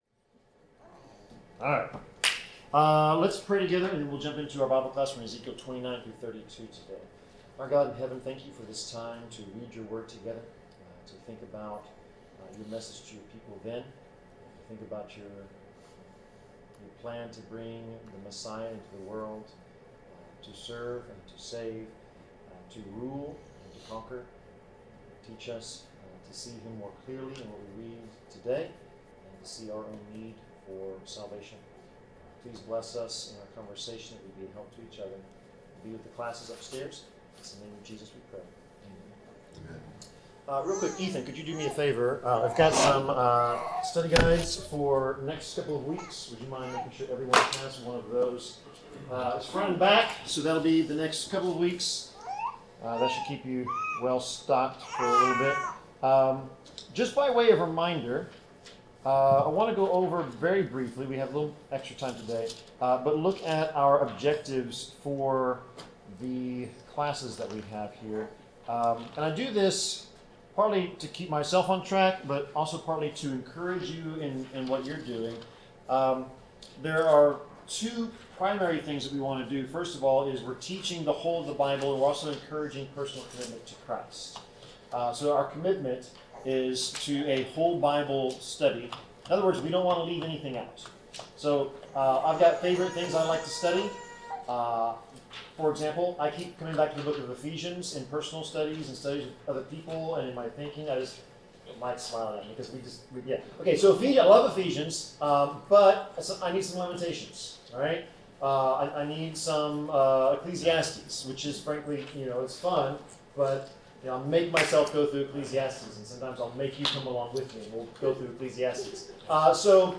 Bible class: Ezekiel 29-31